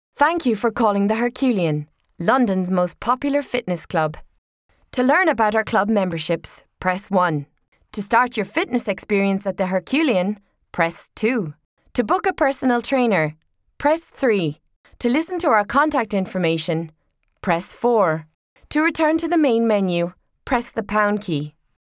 I have a bright friendly voice that can also be quite serious but all the while dynamic and interesting.
Sprechprobe: Werbung (Muttersprache):